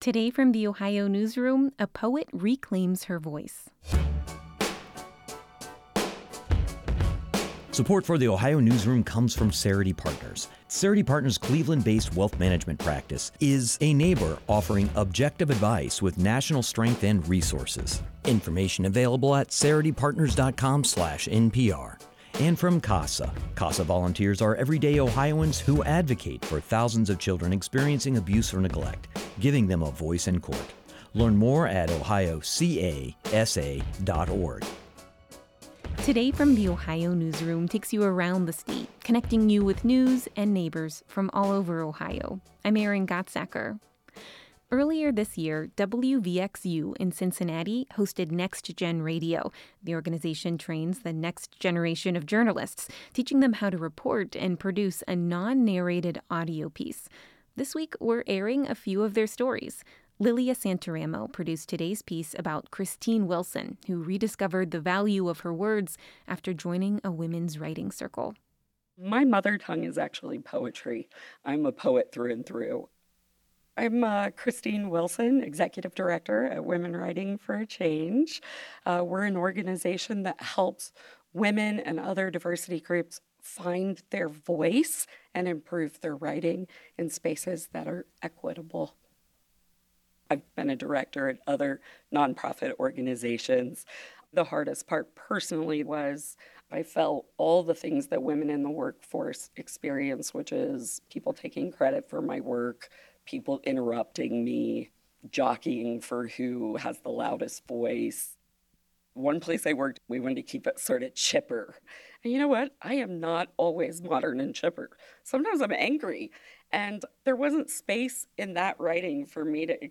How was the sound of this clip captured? The organization trains the next generation of journalists, teaching them how to report and produce a non-narrated audio piece.